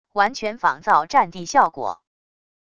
完全仿造战地效果wav音频